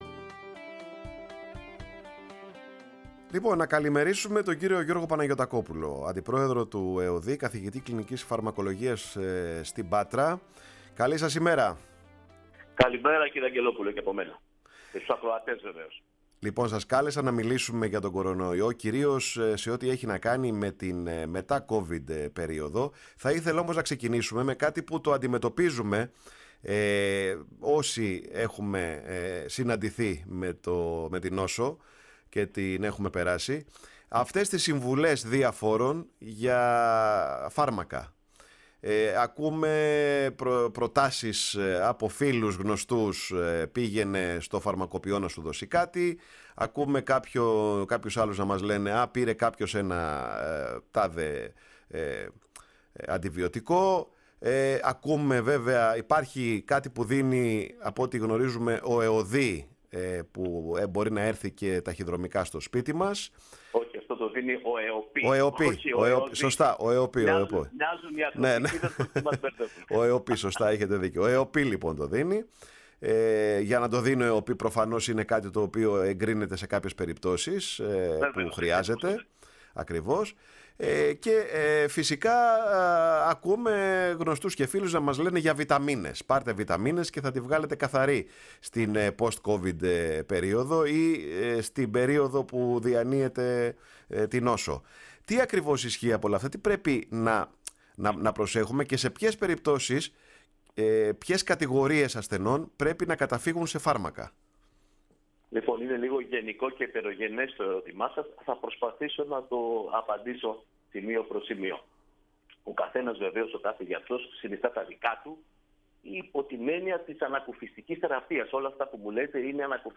Για τη φαρμακευτική αντιμετώπιση της νόσησης με κορωνοϊό, αλλά και για το σύνδρομο post covid – long covid μίλησε στη Φωνή της Ελλάδας και στην εκπομπή ΔΥΟ ΟΨΕΙΣ – ΠΟΛΛΕΣ ΑΠΟΨΕΙΣ ο καθηγητής Κλινικής φαρμακολογίας στο πανεπιστήμιο Πατρών και αντιπρόεδρος του ΕΟΔΥ, Γιώργος Παναγιωτακόπουλος.